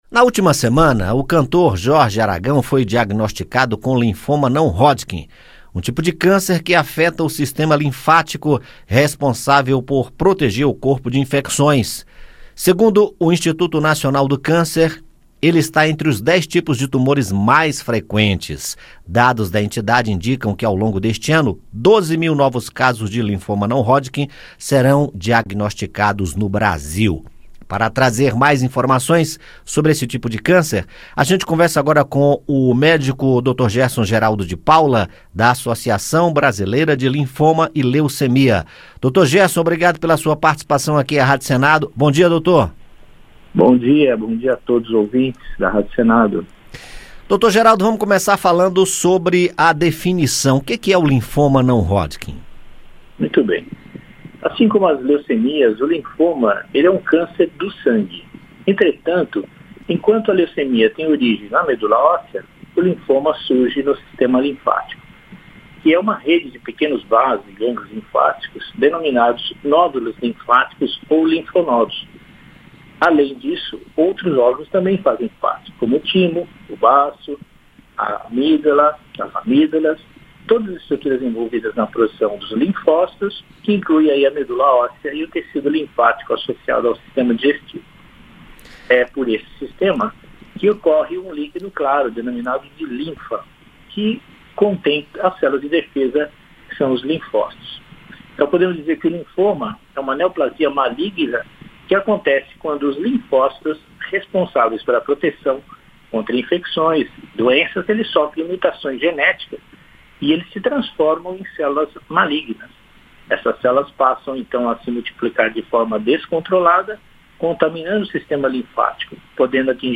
Onco-Hematologista explica o que é e como é o tratamento do linfoma não Hodgkin